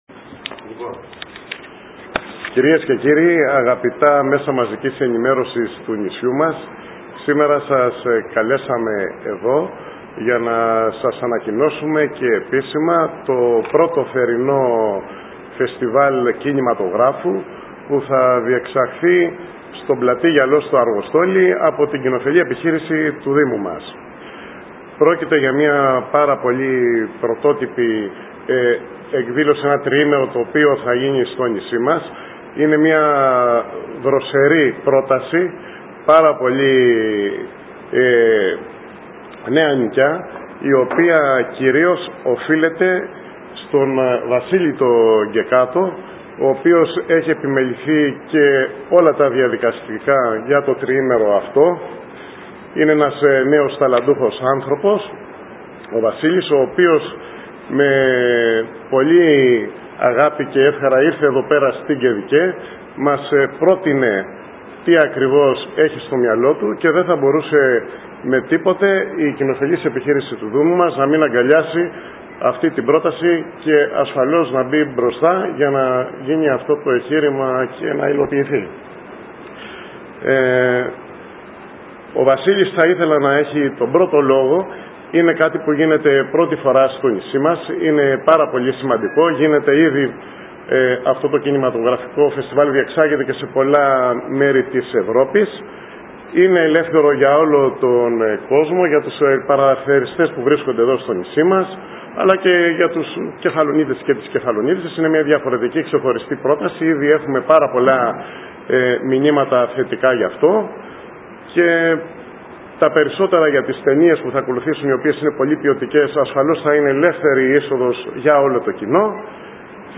σε συνέντευξη τύπου στο Θέατρο